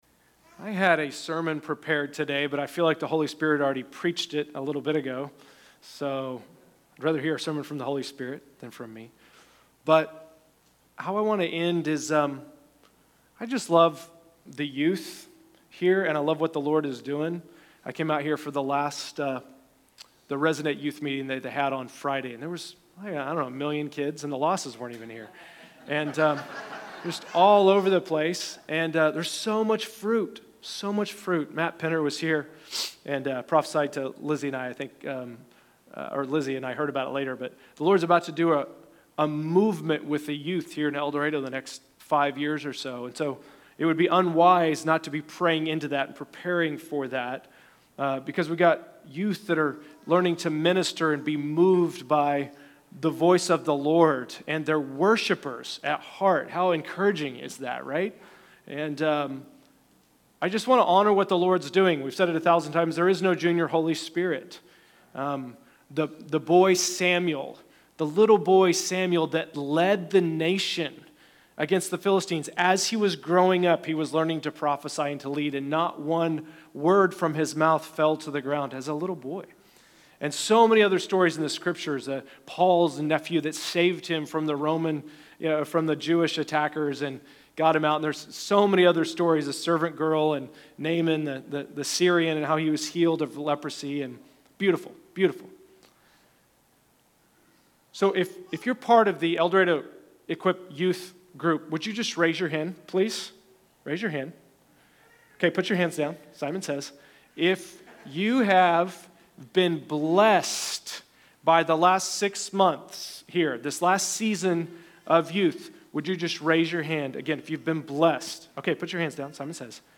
Several Equip youth share about this past “semester” and what blessed them most. Then, each of them speaks a word of blessing over the body in El Dorado.